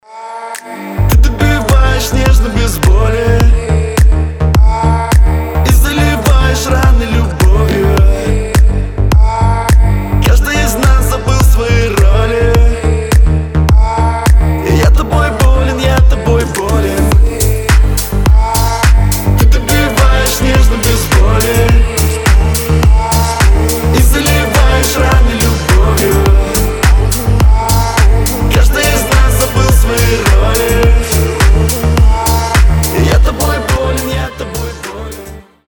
мужской голос
deep house
атмосферные
релакс
чувственные